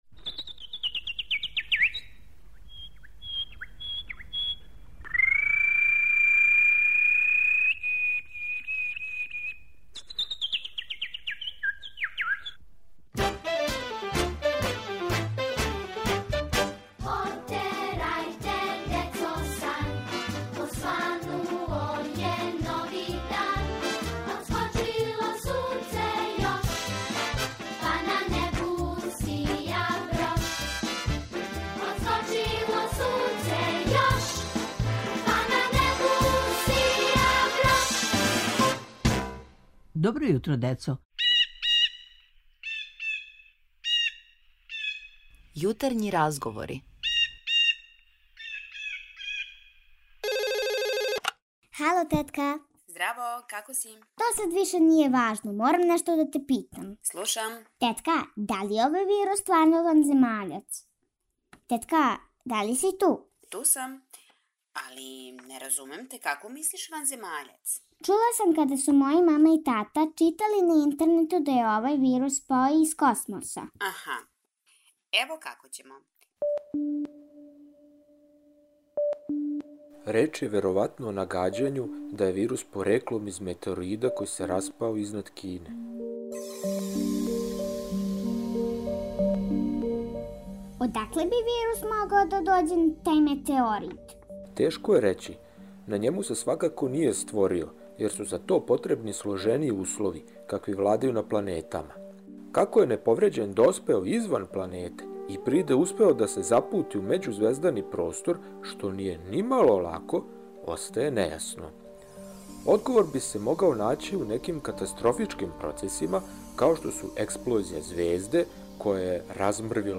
У серијалу "Јутарњи разговор" једна девојчица пита се да ли је вирус корона можда неки ванземаљац ако кажу да је дошао из космоса?! На сва питања одговориће наш стручњак, а ви слушајте пажљиво, да не бисмо нагађали!